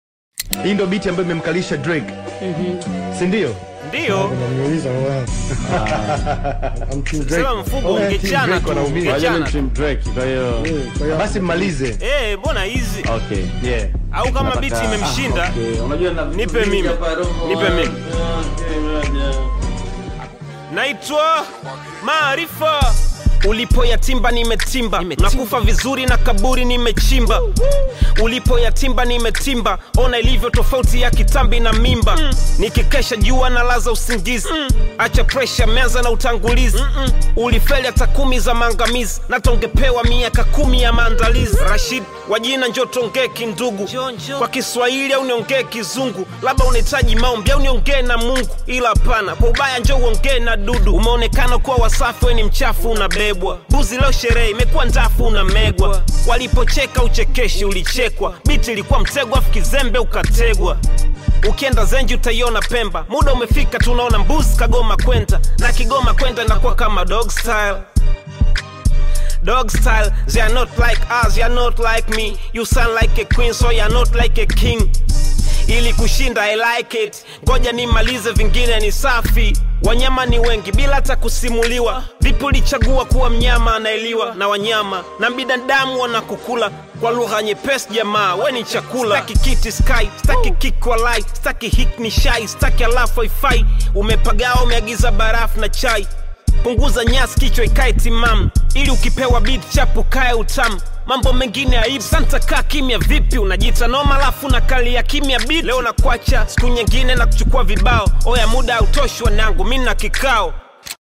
Tanzanian Bongo Flava artist, singer, and songwriter